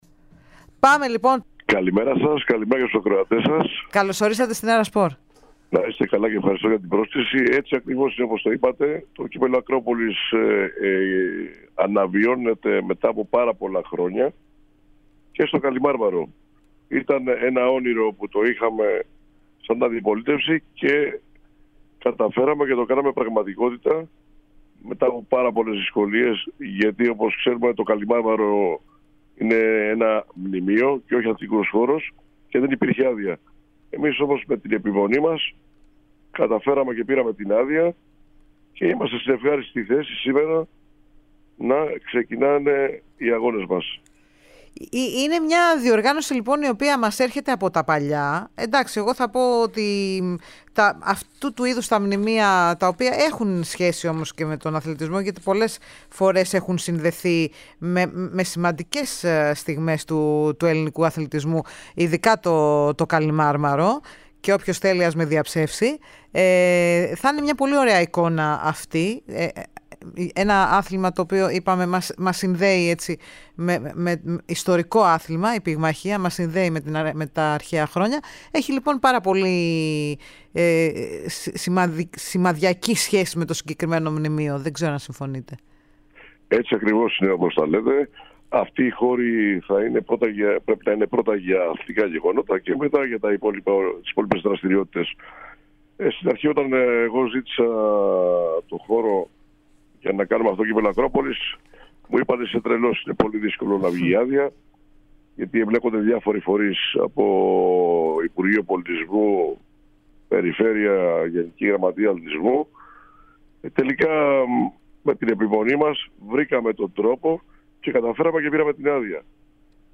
μιλά στον αέρα της ΕΡΑ Σπορ για τo τουρνουά που θα πραγματοποιηθεί έπειτα από πολλά χρόνια στις 16-19 Ιουνίου.
συνέντευξή